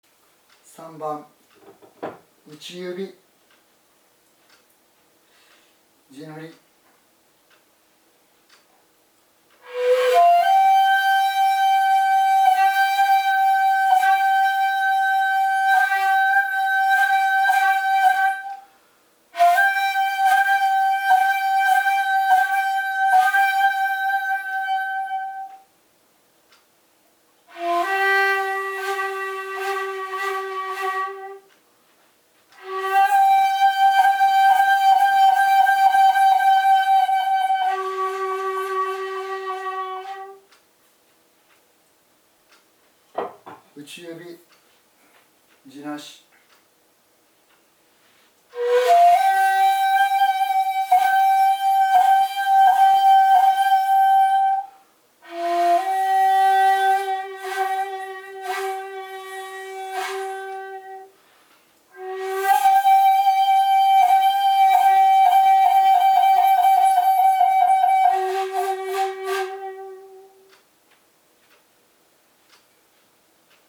今回は如何に地無し管で琴古流本曲が生き返ってくるか、を地塗り管と実際に吹き比べてみたいと思います。
３．打ち指、コロコロ、その他の特殊音がきわめて効果的に発音できる。　特に地無し管での「打ち」は能楽における小鼓の打つ音色に似ている。